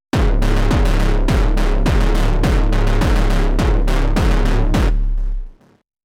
both approaches combined …